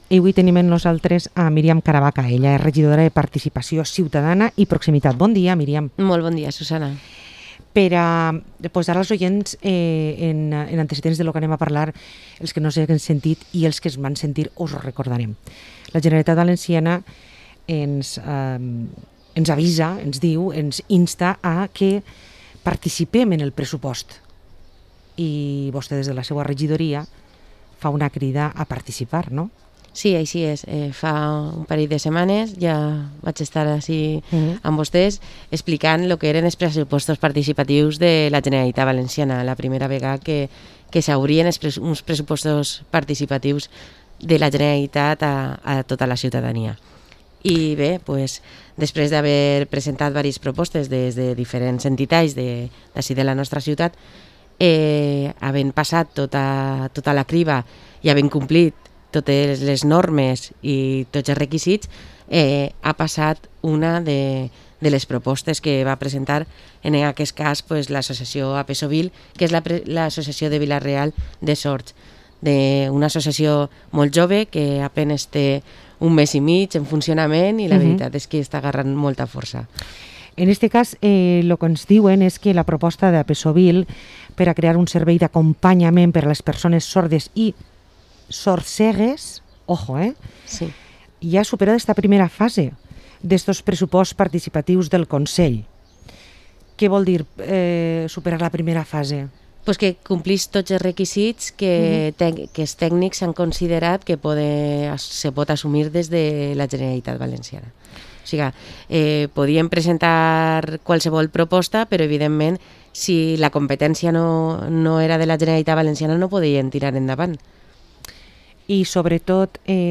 Entrevista a la concejala de Participación y Proximidad Ciudadana de Vila-real, Miriam Caravaca